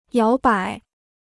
摇摆 (yáo bǎi) Free Chinese Dictionary